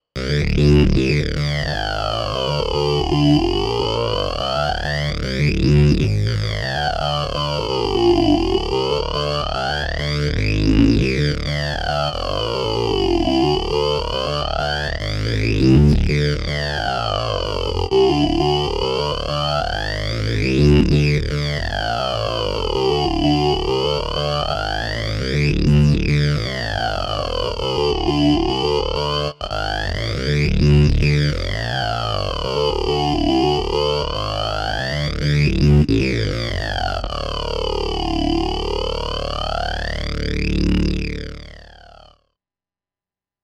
Snarling-Pig-M3---riff-di-Endless-Sacrifi.mp3